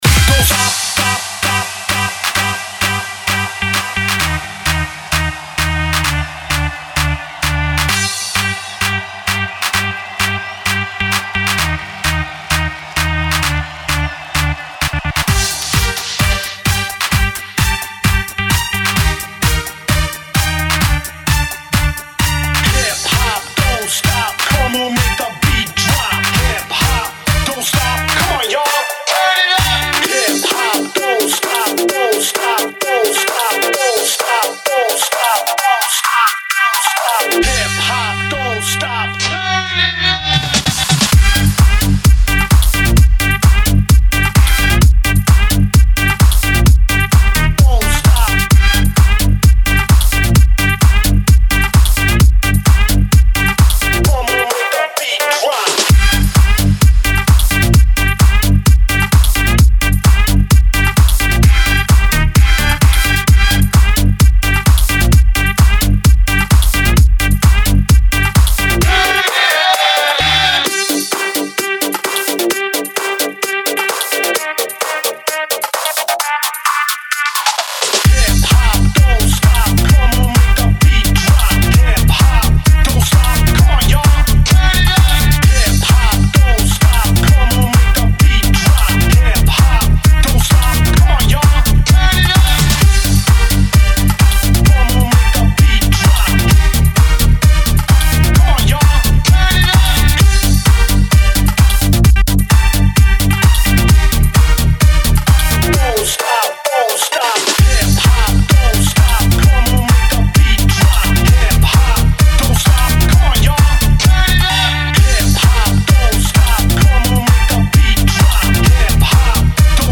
хип-хап_
hip_hap_.mp3